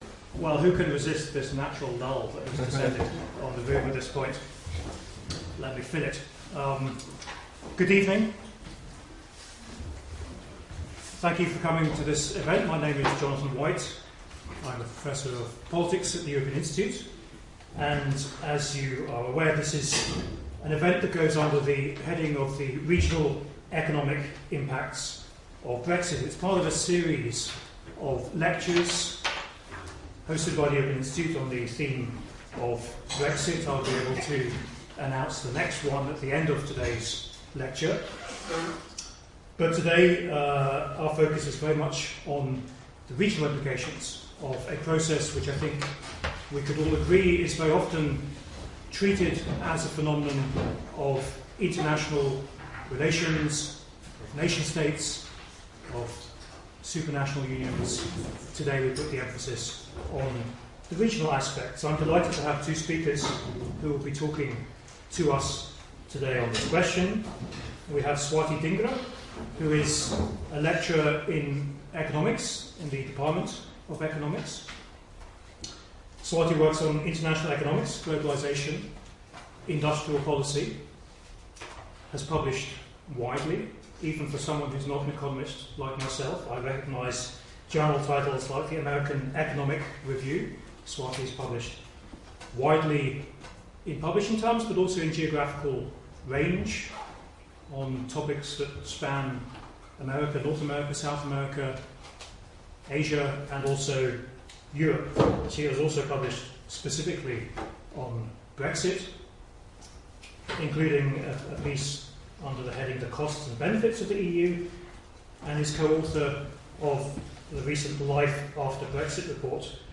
Will Brexit exacerbate existing disparities in living standard across the UK, or might it narrow differences? In this lecture we'll consider what we know about local impacts, identify the biggest losers from changes in trade costs and consider what other factors might matter if we want to better understand the likely impact on our towns and cities.